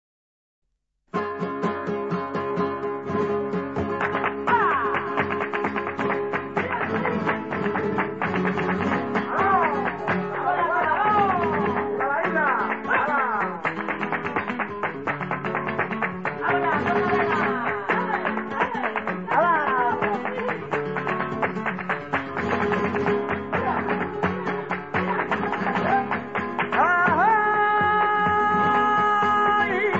Bulerias gitanas